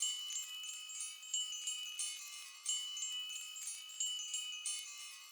fx_bells_1